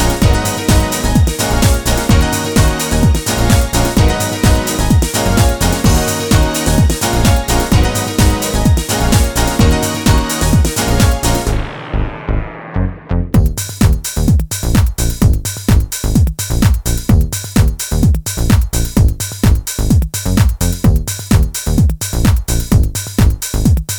no Backing Vocals Dance 3:37 Buy £1.50